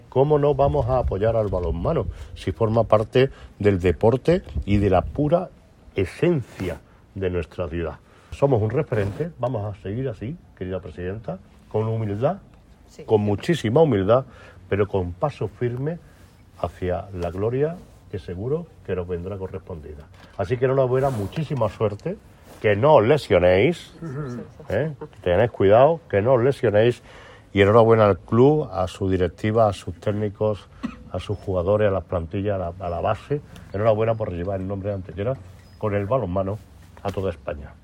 El alcalde Manolo Barón y el teniente de alcalde Juan Rosas acompañan al equipo antequerano en su puesta de largo ante la prensa con motivo del inicio de una nueva temporada deportiva, en la que el Ayuntamiento de Antequera volverá a ofrecer su colaboración como principal sponsor.
Cortes de voz